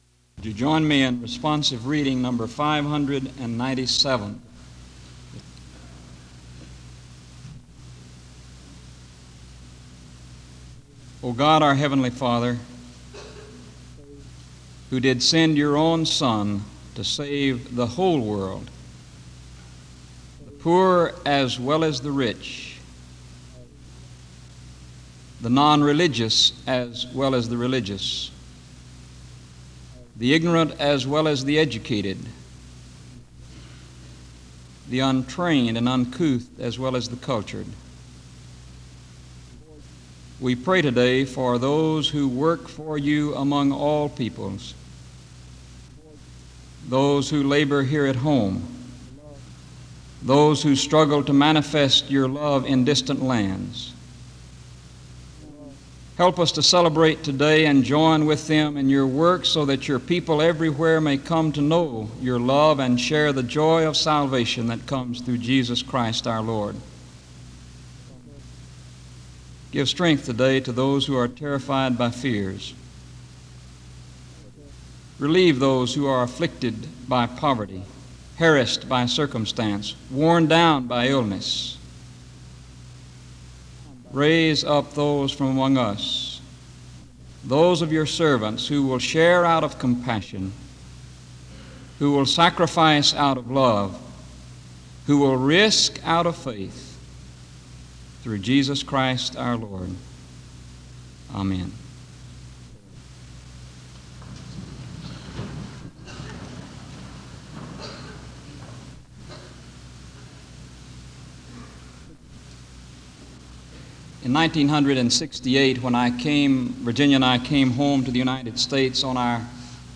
SEBTS Missionary Day Address
SEBTS Chapel and Special Event Recordings - 1970s